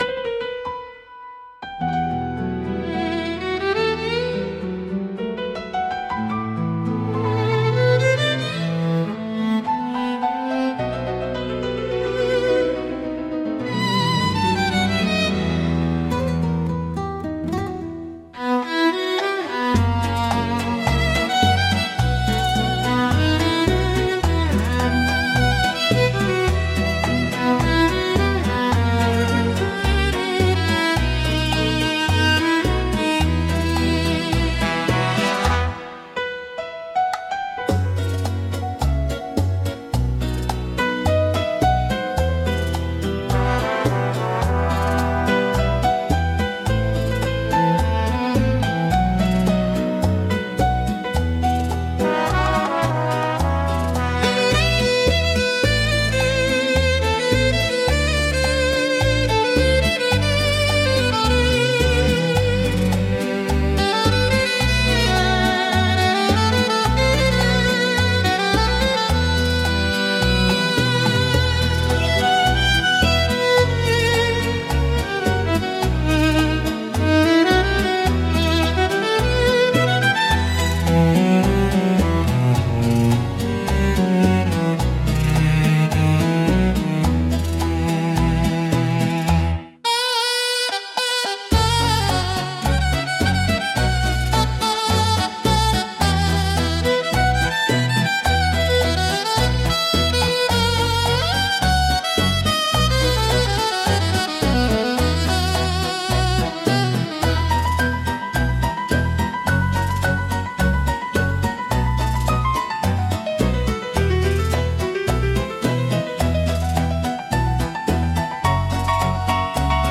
instrumental 10